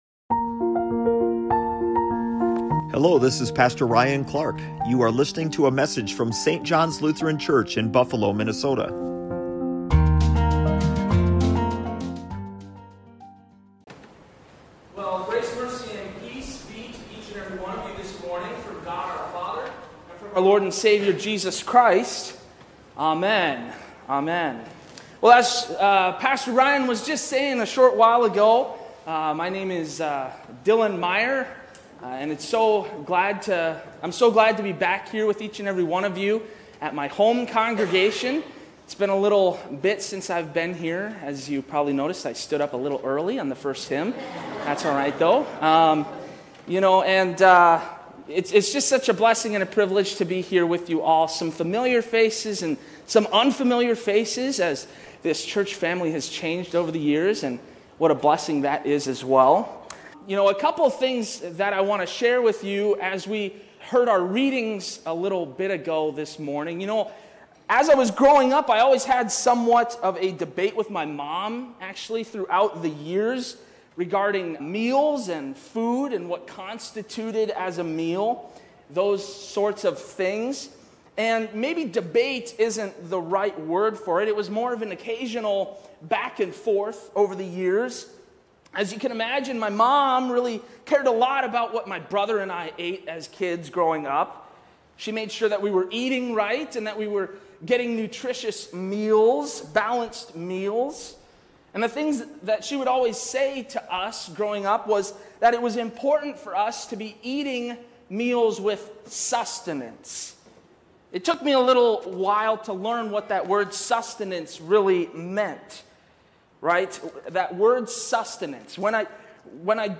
Join him considering the sustenance versus subsistence debate in this message from John 6 about our Bread of Life.